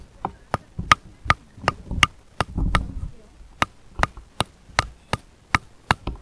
두드리는02.ogg